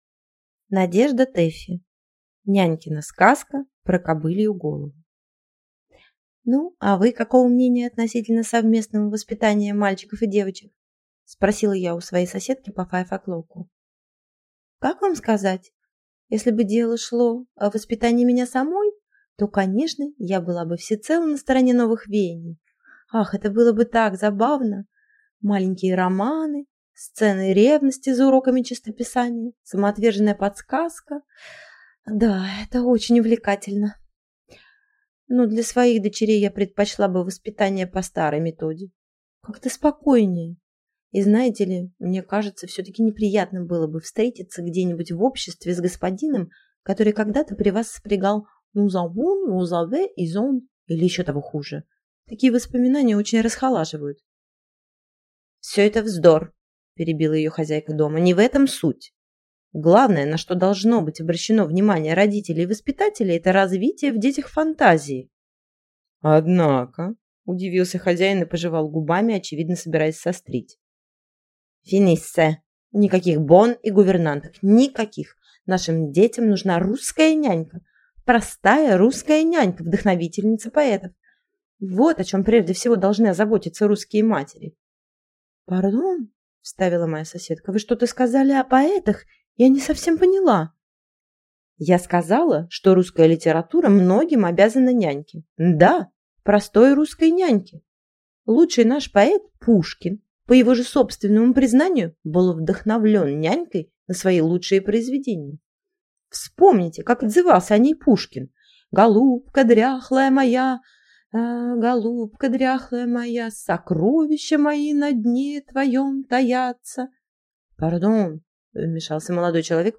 Аудиокнига Нянькина сказка про кобылью голову | Библиотека аудиокниг